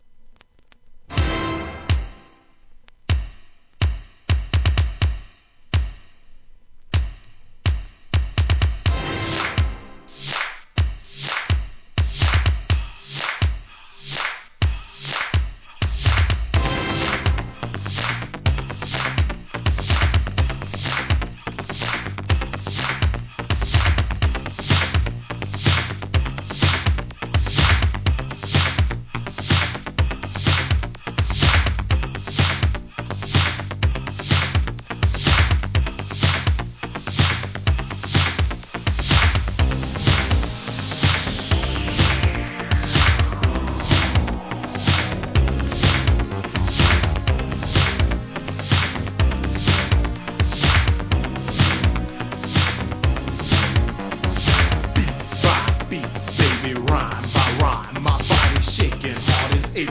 エレクトロ名作!